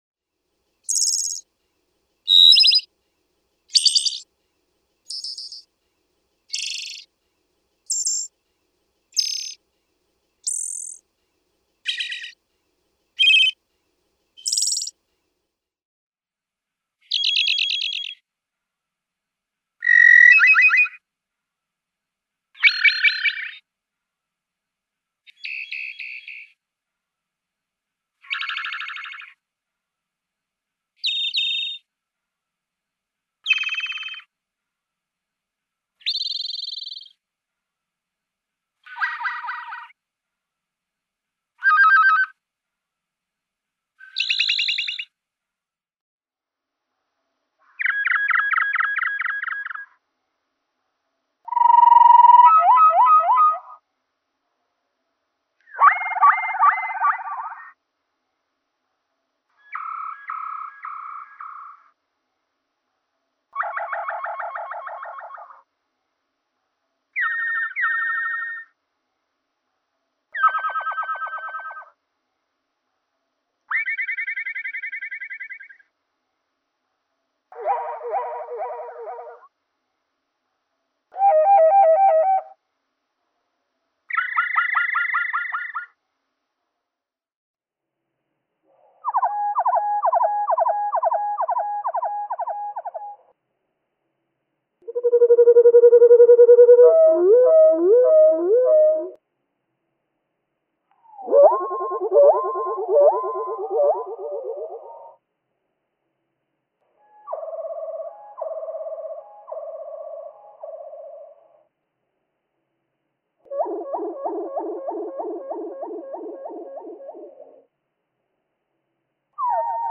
♫442. He sings 11 different flourishes, played here at normal, half, quarter, and one-eighth speed. (2:30)
442_Wood_Thrush.mp3